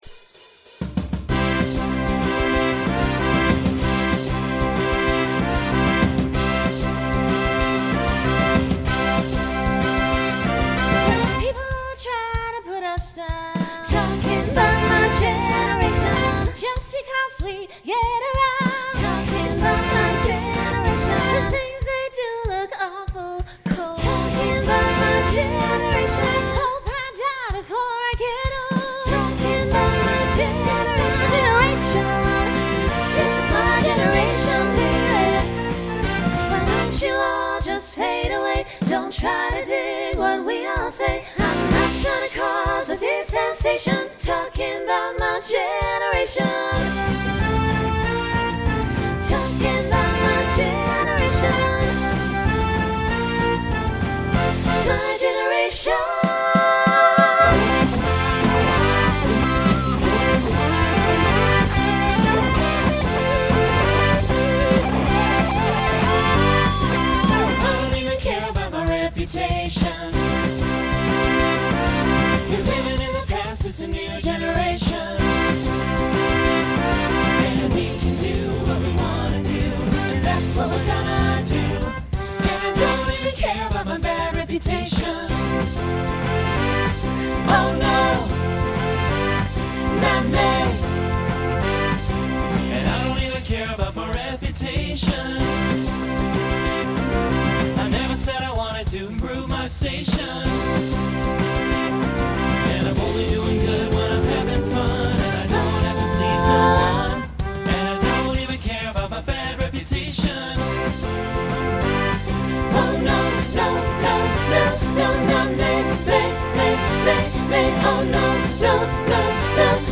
Genre Rock Instrumental combo
Transition Voicing Mixed